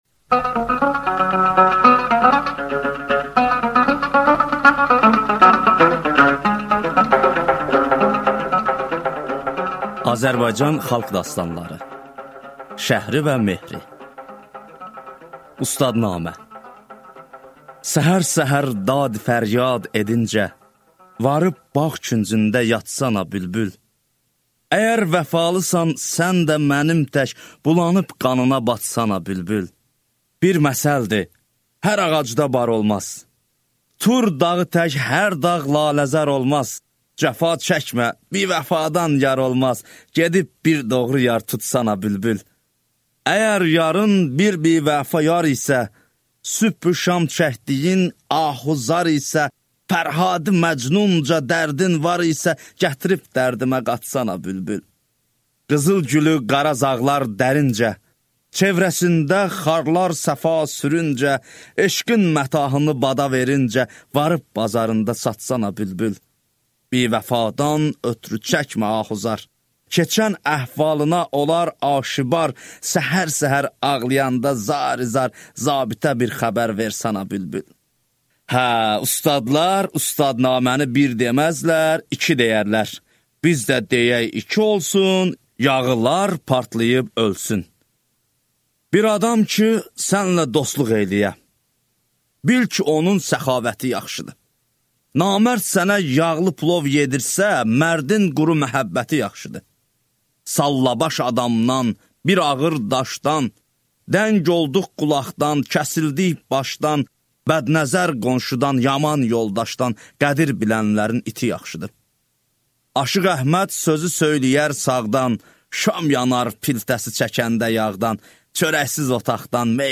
Аудиокнига Azərbaycan xalq dastanları 1-ci hissə | Библиотека аудиокниг